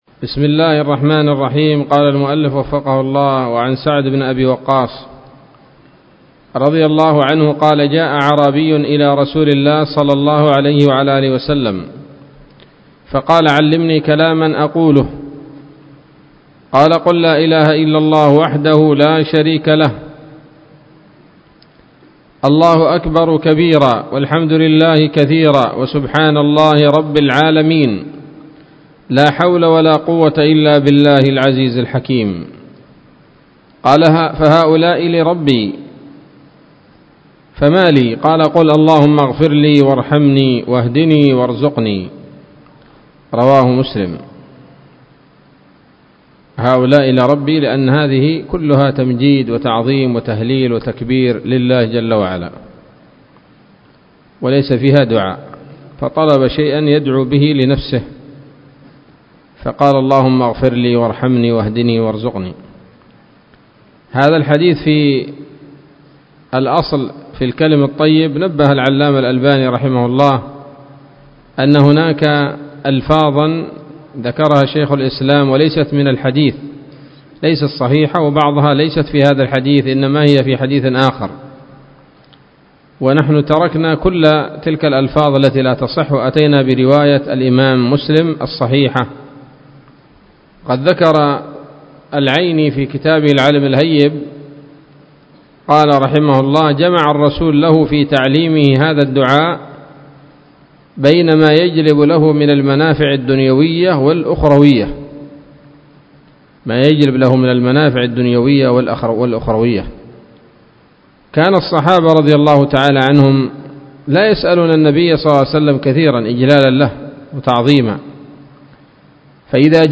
الدرس الثامن من رياض الأبرار من صحيح الأذكار